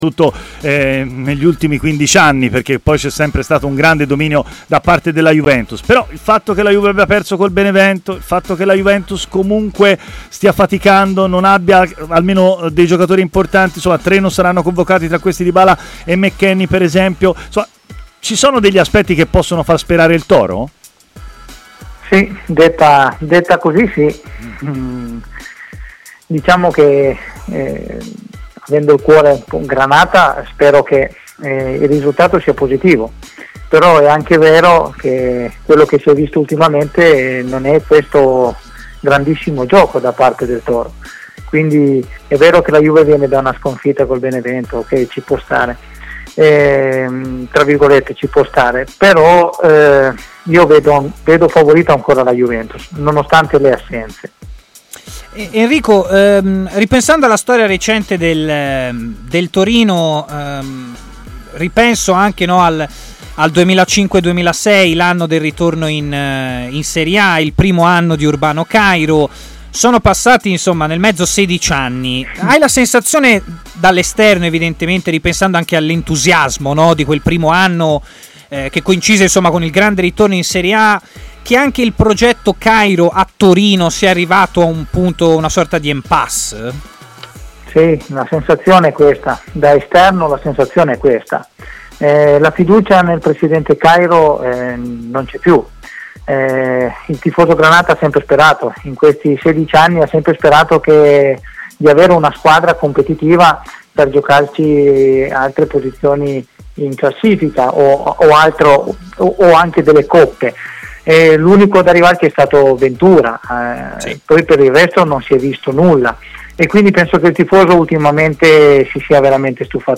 è intervenuto in diretta a Stadio Aperto, trasmissione di TMW Radio